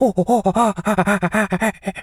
monkey_chatter_11.wav